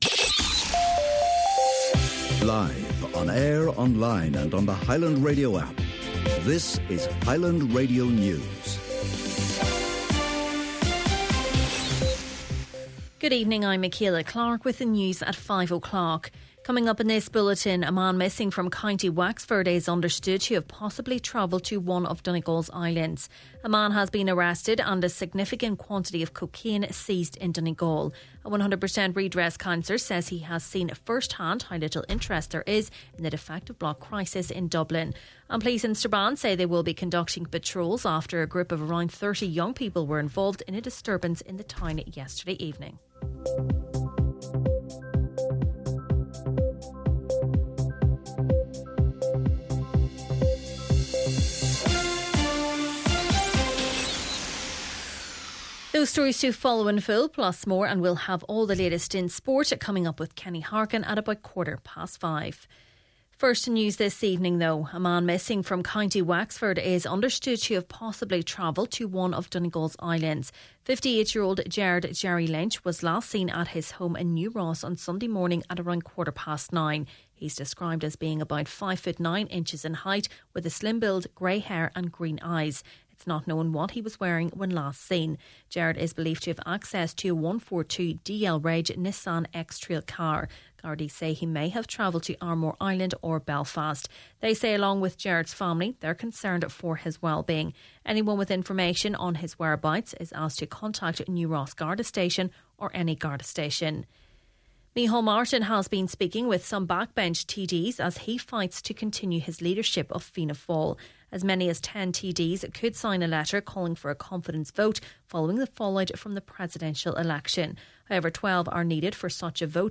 Main Evening News, Sport and Obituaries – Tuesday, October 28th